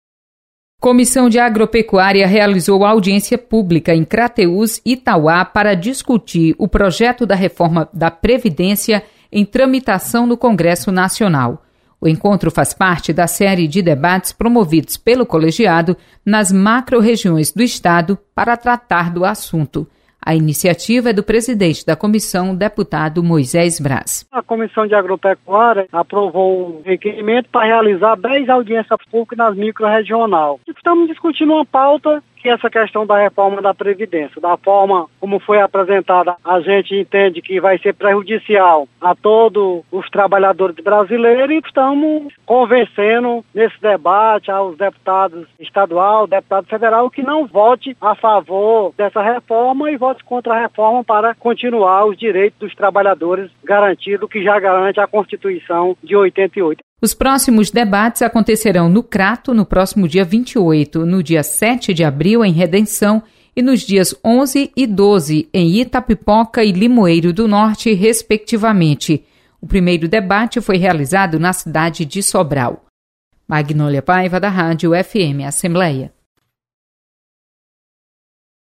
Comissão debate reforma da previdência. Repórter